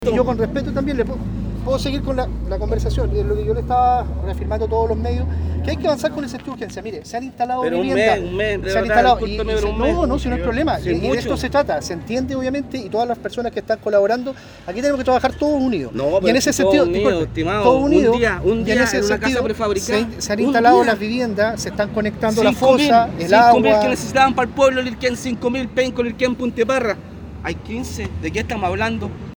Antes de poder comenzar el punto de prensa, la ministra fue increpada por vecinos que acusan un lento avance en la reconstrucción.
En ese sentido, un habitante de una de las comunas afectadas por el megaincendio increpó a la titular de la cartera de la Mujer y Equidad de Género, exigiéndole una fecha para el arribo de casas de emergencia.